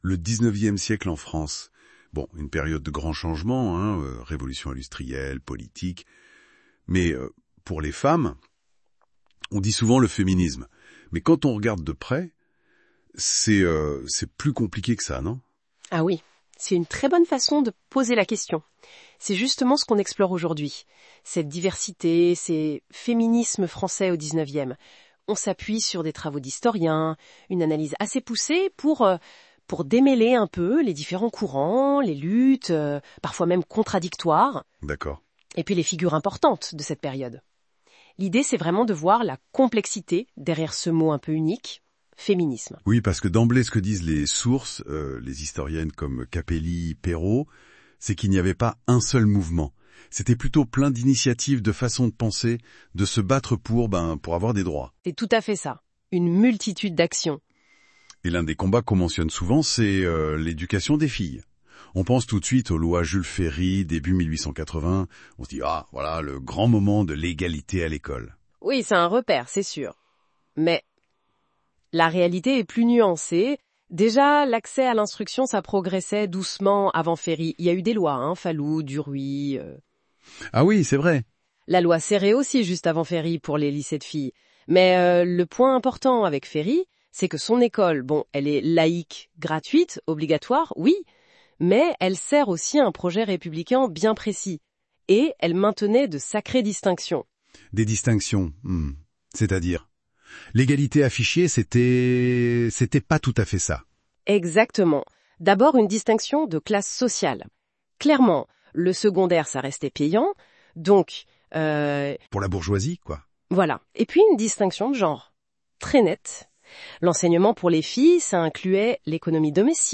Il s'agit d'une synthèse qui reprend les éléments essentiels du thème. Cette discussion a été réalisée grâce à l'IA.